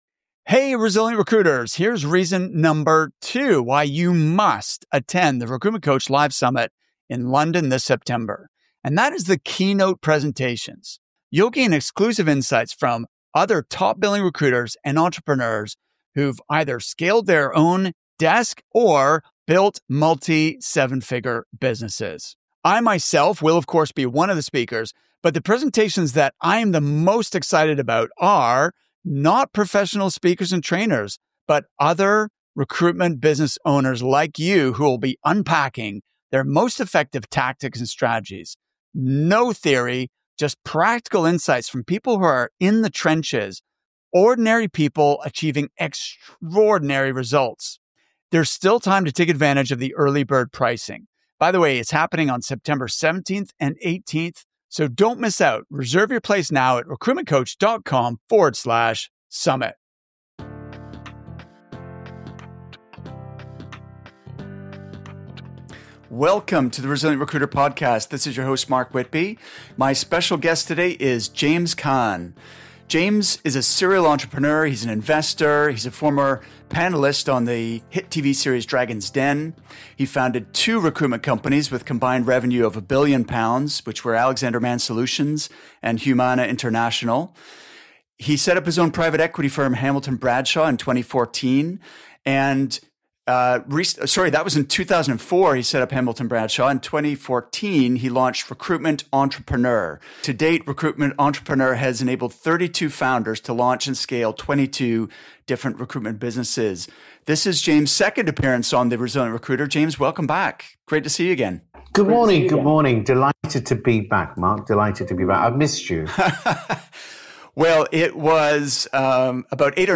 If so, you’re going to love my interview with James Caan, who joins us for his second time on the show.